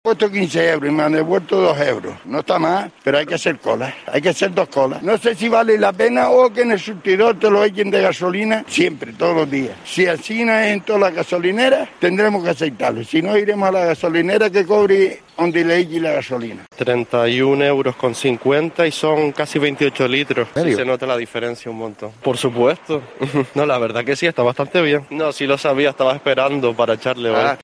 Testimonios de conductores repostando